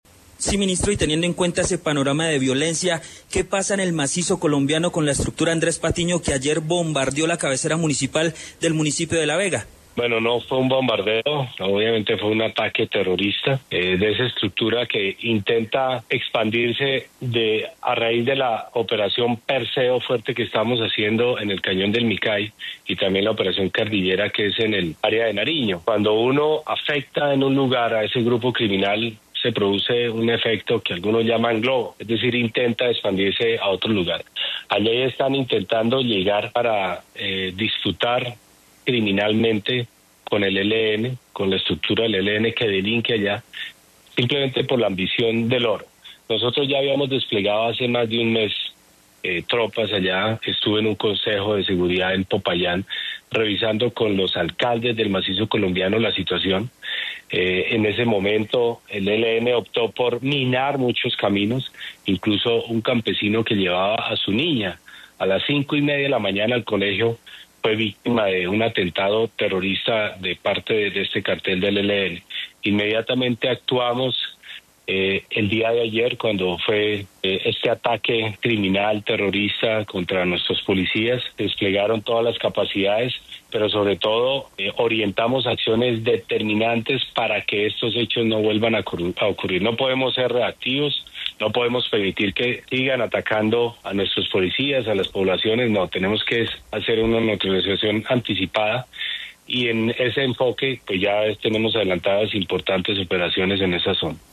El ministro de Defensa, Pedro Sánchez, habló en La W sobre el ataque terrorista en La Vega, Cauca.